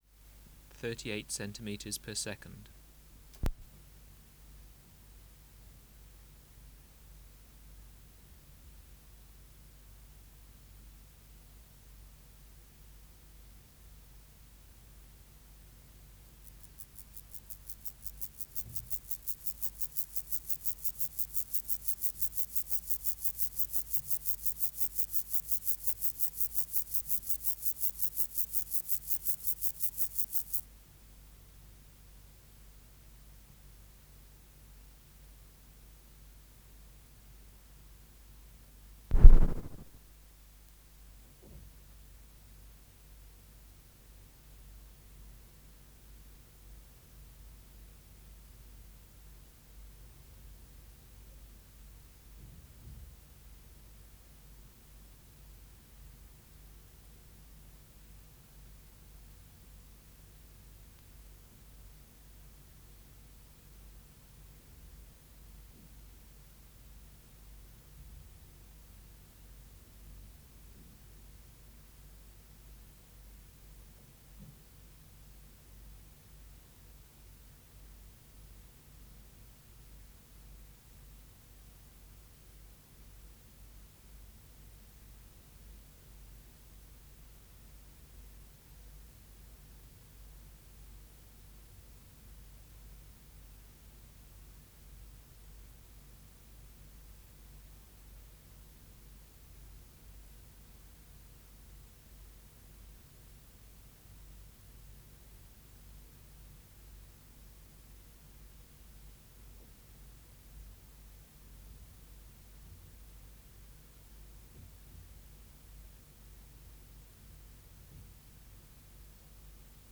Project: Natural History Museum Sound Archive Species: Chorthippus (Glyptobothrus) vagans
Recording Location: BMNH Acoustic Laboratory
Reference Signal: 1 kHz for 10 s
Substrate/Cage: Recording cage
Microphone & Power Supply: Sennheiser MKH 405 Distance from Subject (cm): 10